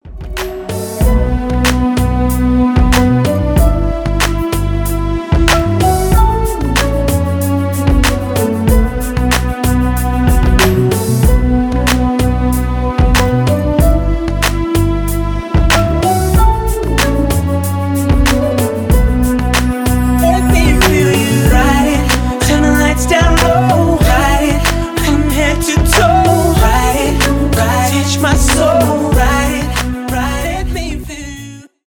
соул , хип-хоп
rnb